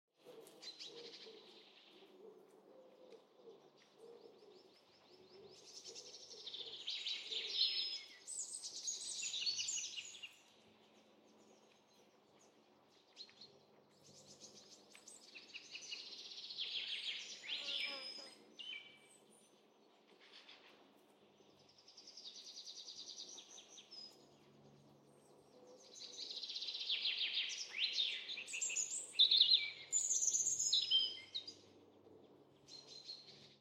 دانلود صدای جنگل 1 از ساعد نیوز با لینک مستقیم و کیفیت بالا
جلوه های صوتی